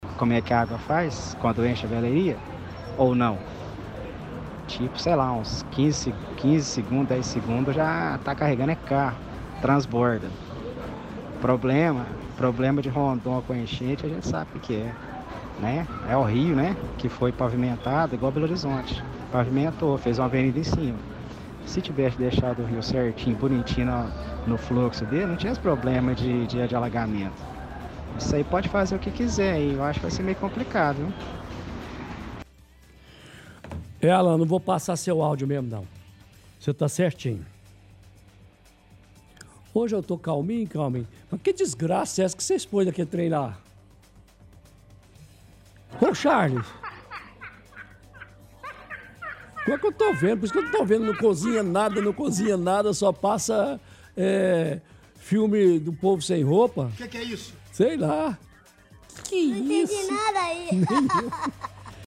– Ouvinte fala que galeria aberta na avenida Rondon Pacheco não resolveria.